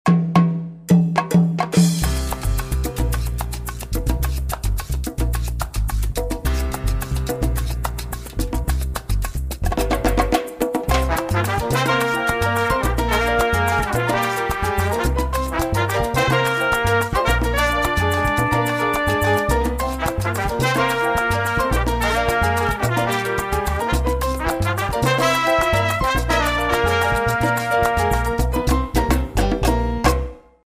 Latin Jazz